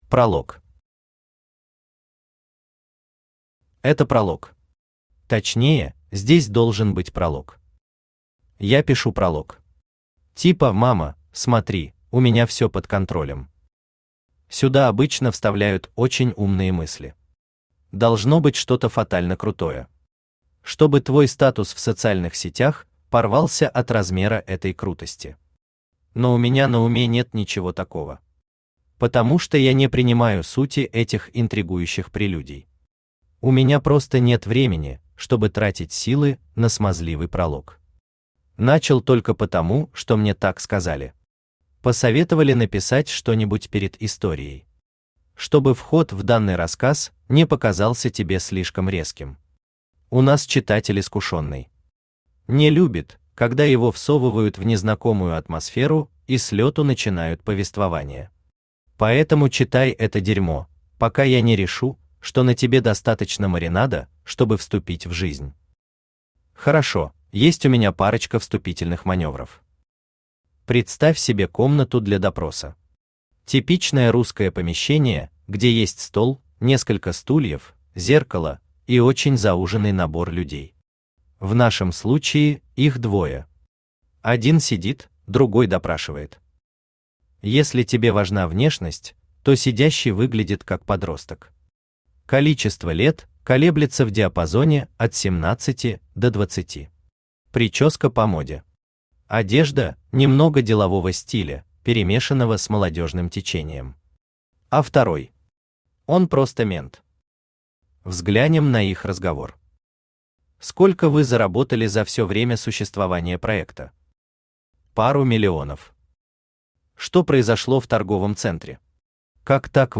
Аудиокнига Что-то там про подростков | Библиотека аудиокниг
Aудиокнига Что-то там про подростков Автор Александр Александрович Федоров Читает аудиокнигу Авточтец ЛитРес.